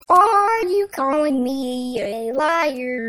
Tags: auto tune